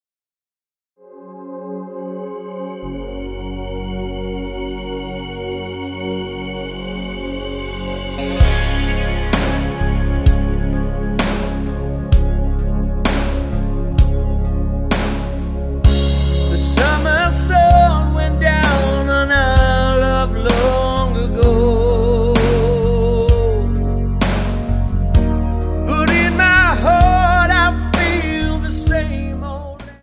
Tonart:C# Multifile (kein Sofortdownload.
Die besten Playbacks Instrumentals und Karaoke Versionen .